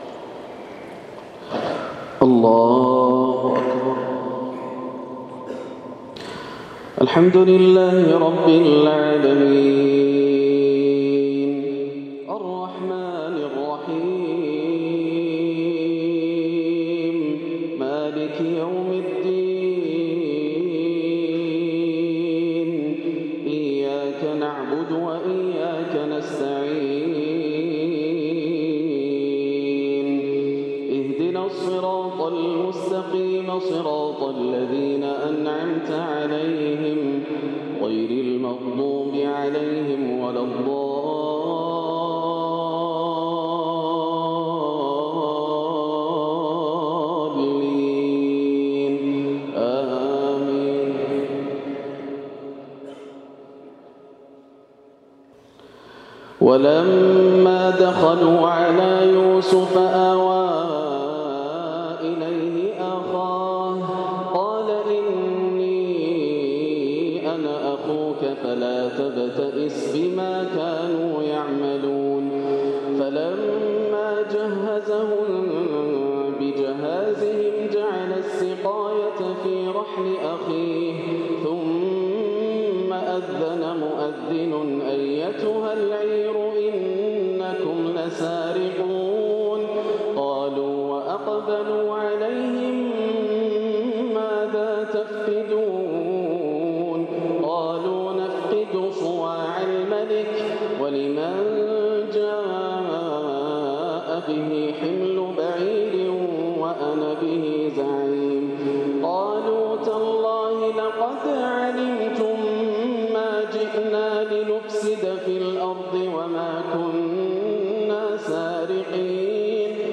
ما تيسر من سورة "يوسف" بالبيات والحجاز والنهاوند والصبا يبدع الشيخ بالبكيرية بالقصيم 6‐6 > عام 1439 > الفروض - تلاوات ياسر الدوسري